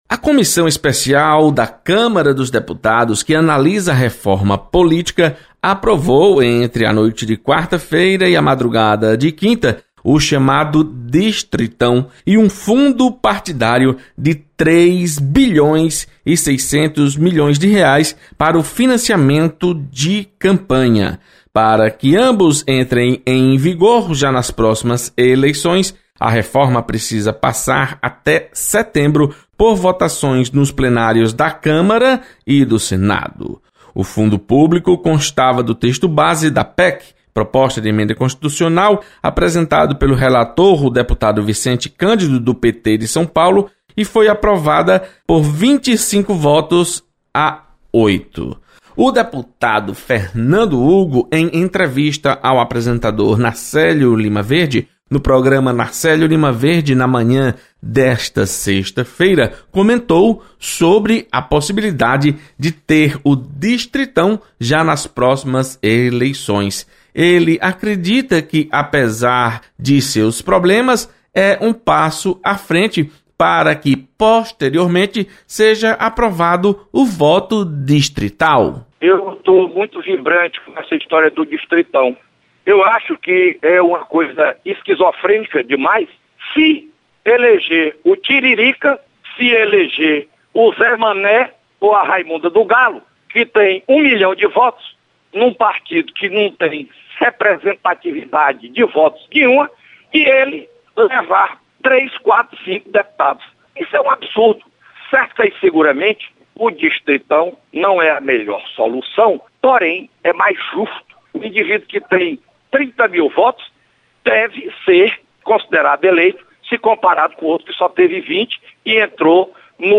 Deputado Fernando Hugo comenta sobre o "distritão" e o fundo partidário.